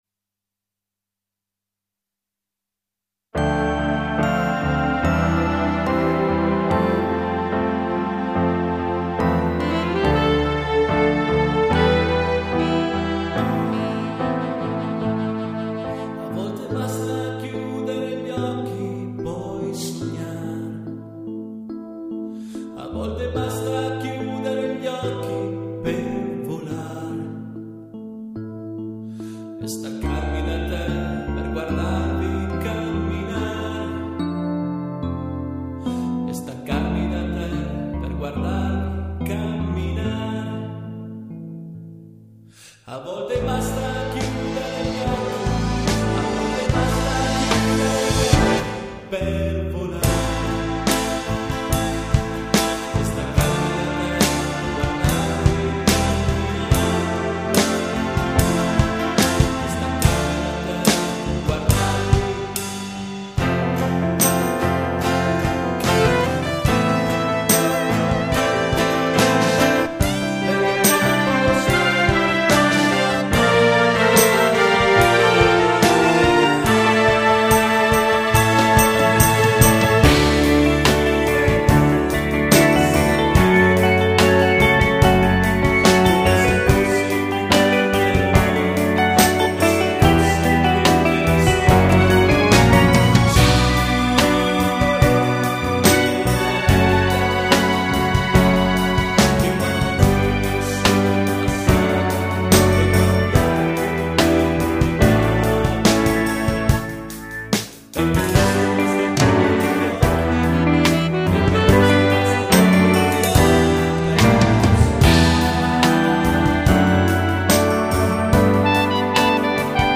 Luogo esecuzioneCARNOLA
GenerePop